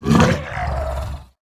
dead_1.wav